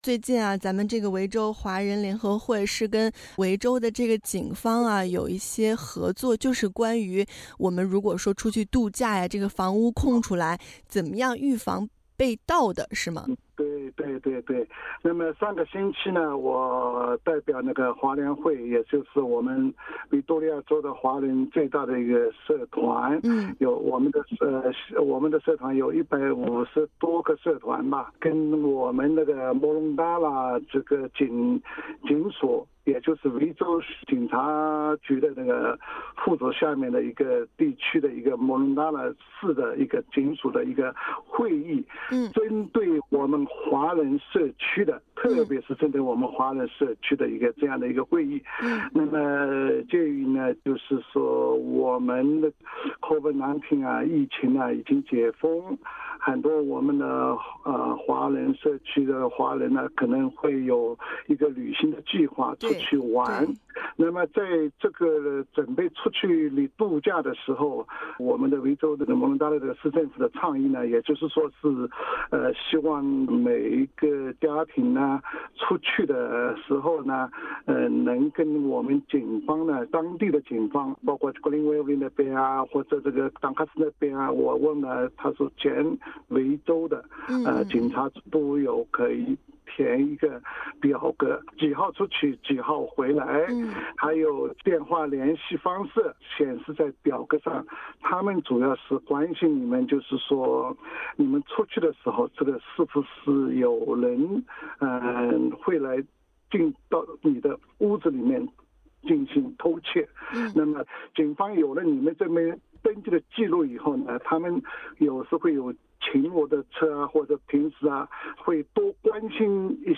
(AAP) Source: AAP SBS 普通话电台 View Podcast Series Follow and Subscribe Apple Podcasts YouTube Spotify Download (11.95MB) Download the SBS Audio app Available on iOS and Android 圣诞新年假期将至，不少人可能会选择去度假旅行。